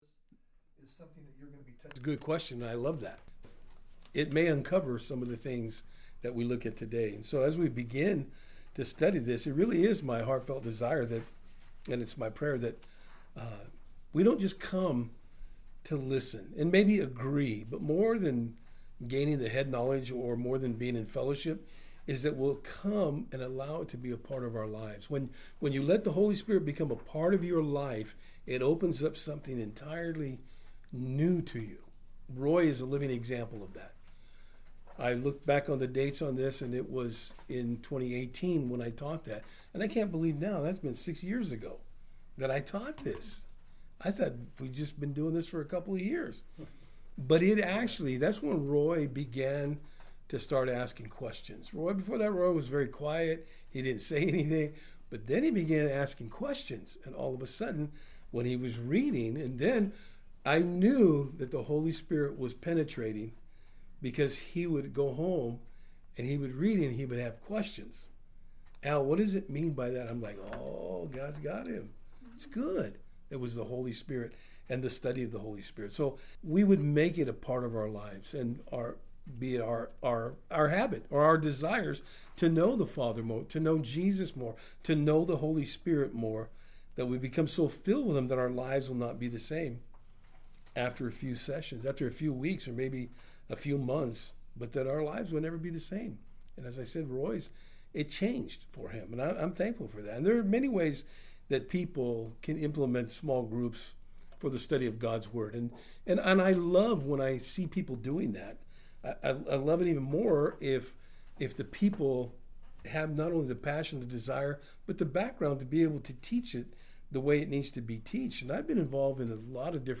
Various Service Type: Thursday Afternoon We will be Looking at the Person of the Holy Spirit in this study today.